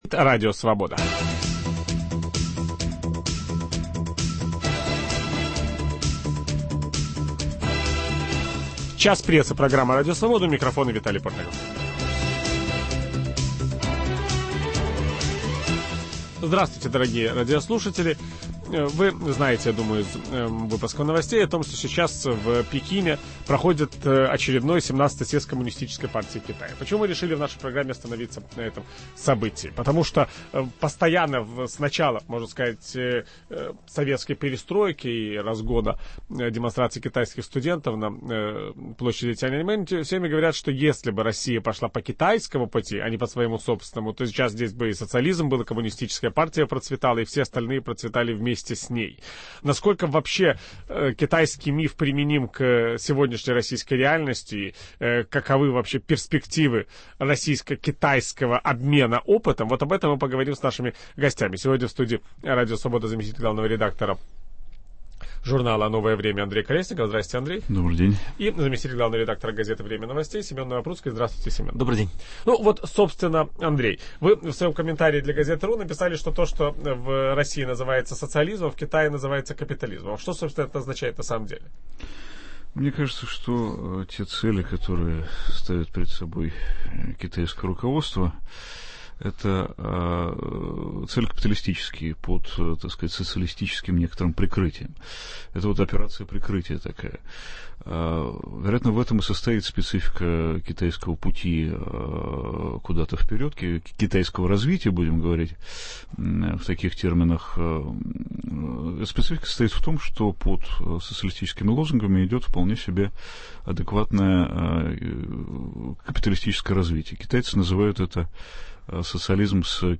Китайский миф и современная Россия. Ведущий программы Виталий Портников беседует